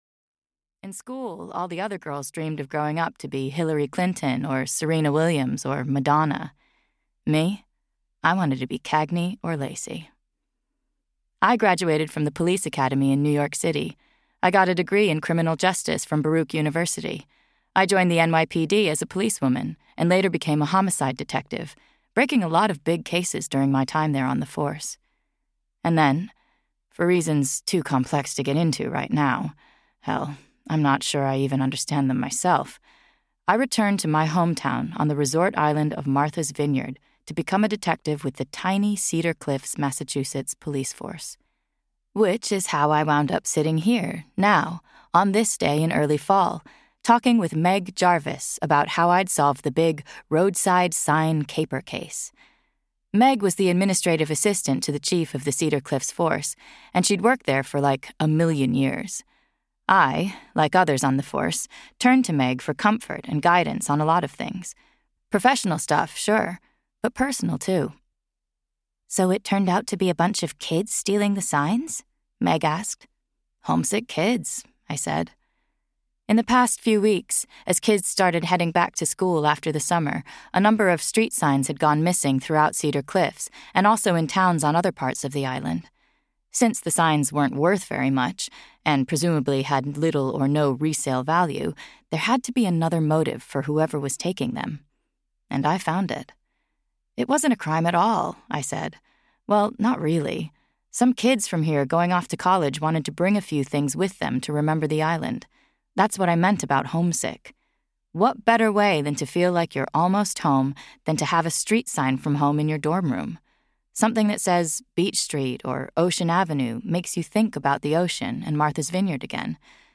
Silent Island (EN) audiokniha
Ukázka z knihy